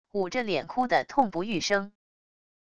捂着脸哭的痛不欲生wav音频